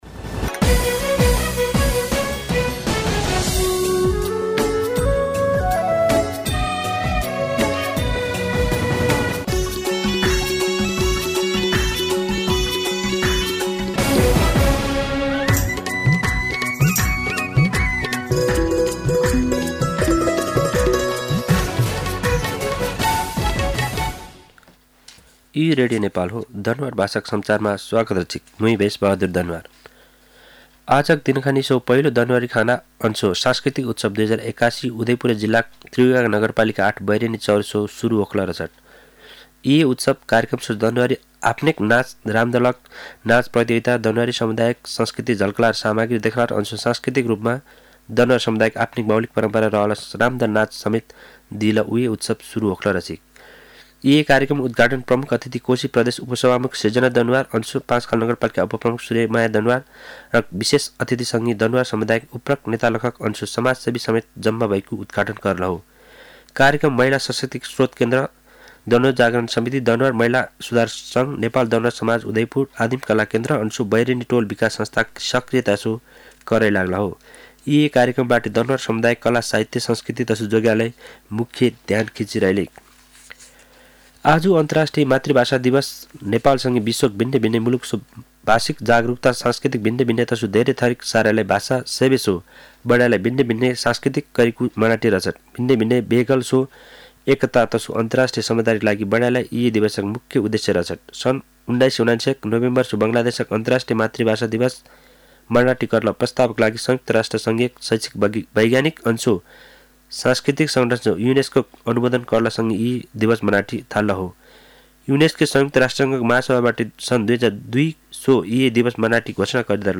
दनुवार भाषामा समाचार : १० फागुन , २०८१
Danuwar-News-1.mp3